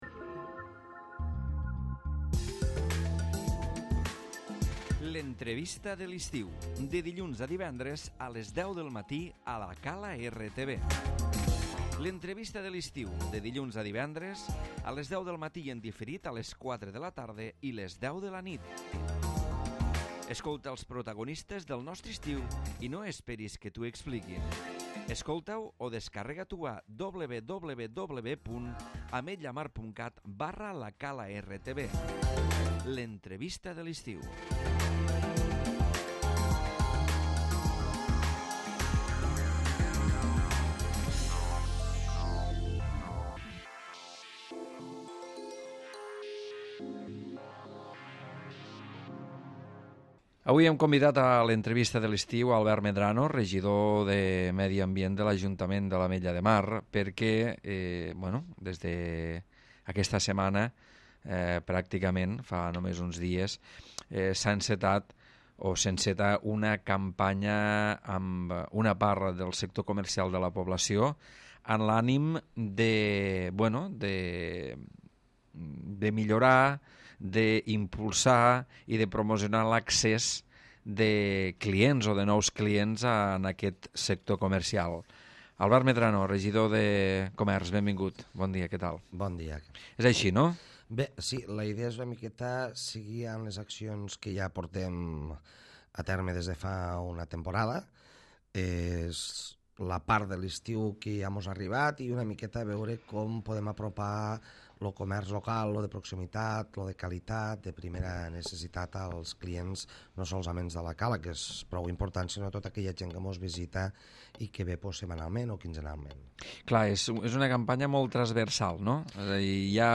El Comerç Local ha començat la campanya d'estiu amb molts atractius pels clients i la novetat que obriran els divendres fins a les 12 de la nit. Albert Medrano, regidor de Comerç en parla a l'Entrevista de l'Estiu.